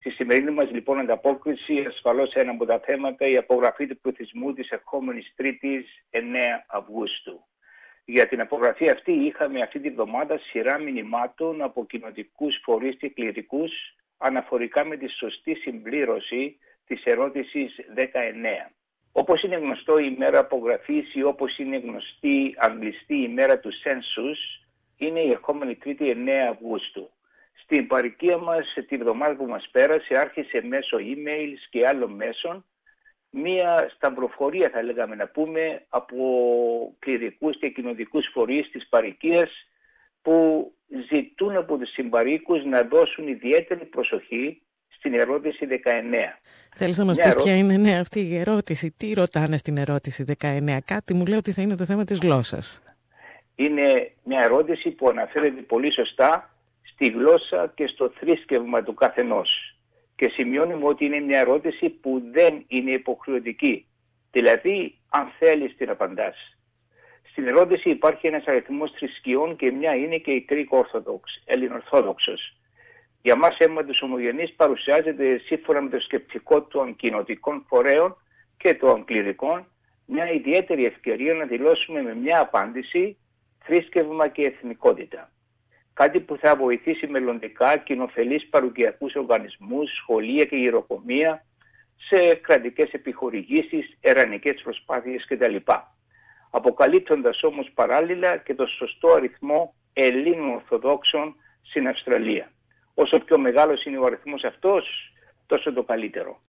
Προσπάθεια από κληρικούς και κοινοτικούς φορείς της Δυτικής Αυστραλίας να ζητήσουν από τους συμπαροίκους να δώσουν ιδιαίτερη προσοχή στην ερώτηση 19, που αφορά στο θρήσκευμα. Ανταπόκριση